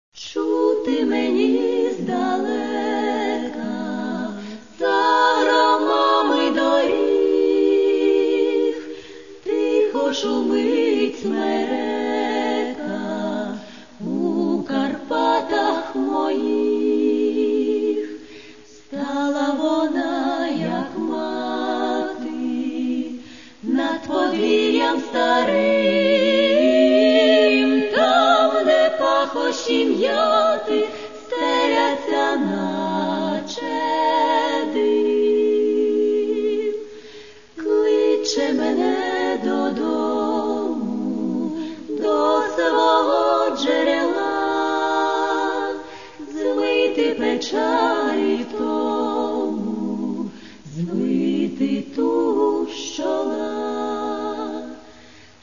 Каталог -> Народна -> Традиційне виконання